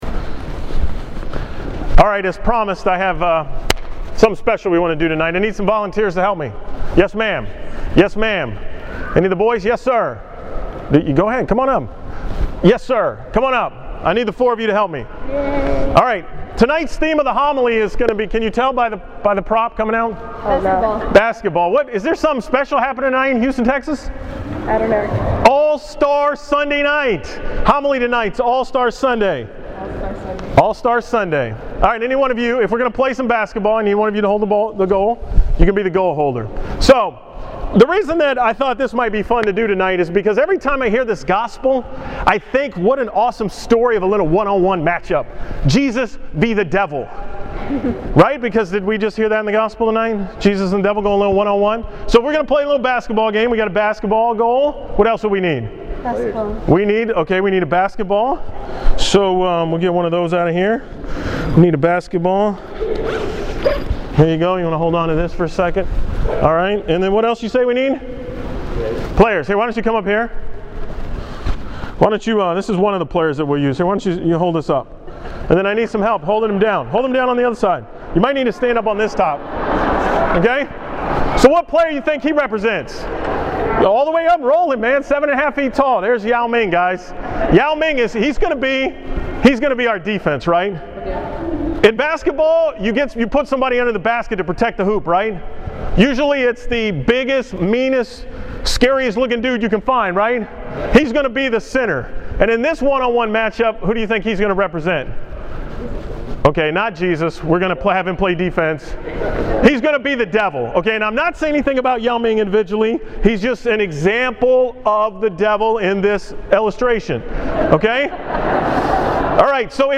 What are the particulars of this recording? From the 5 pm Mass on Sunday, February 17th